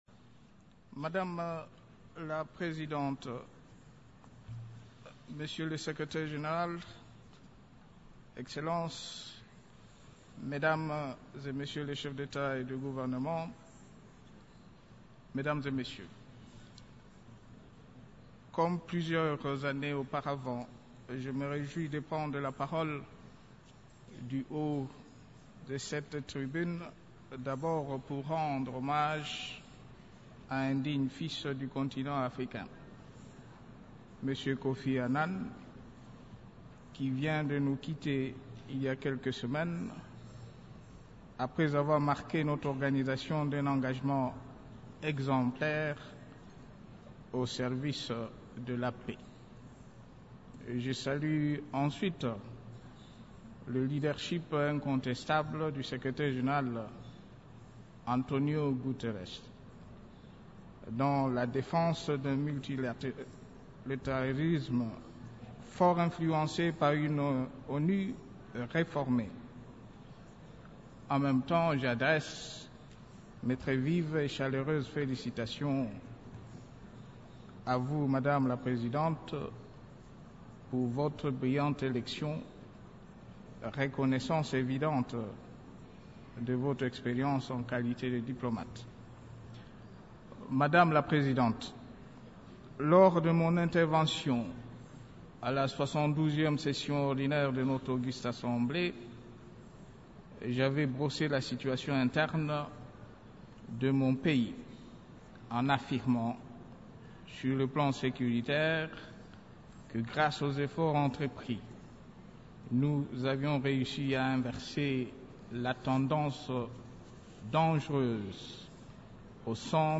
S’exprimant mardi 25 septembre à la tribune de l’ONU lors de la 73e session de l’Assemblée générale de l’organisation, le président de la RDC, Joseph Kabila a dénoncé l’ingérence de certains gouvernements dans les affaires relevant de la souveraineté de son pays.